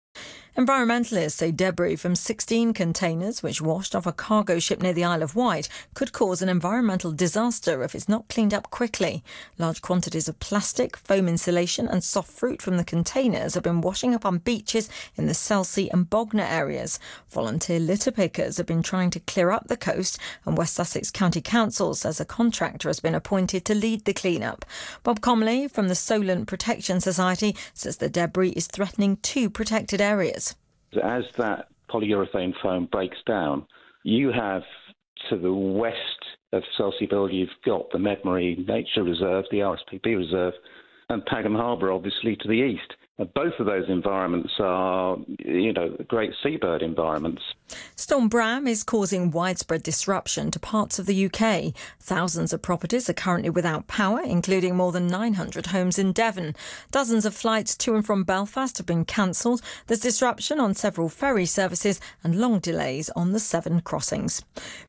carried on national radio news bulletins, emphasising the country-wide interest in the unfolding crisis.